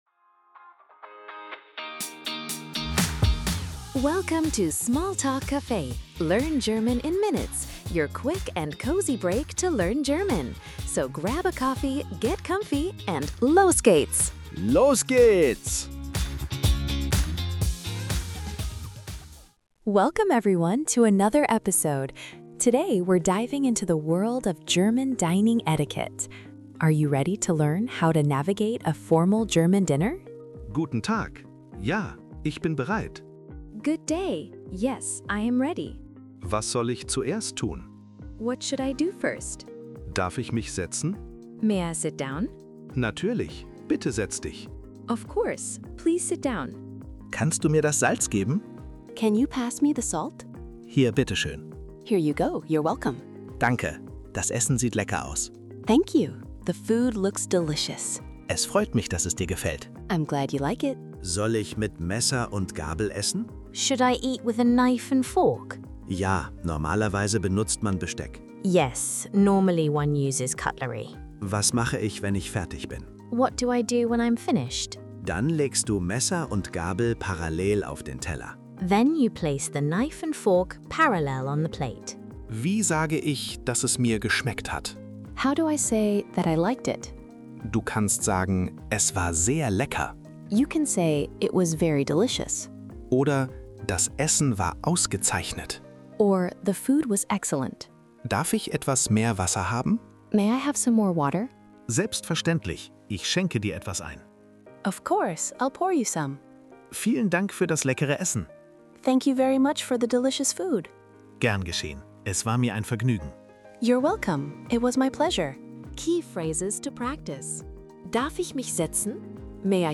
You’ll get real dialogues, quick breakdowns, and fun tips in under 5 minutes.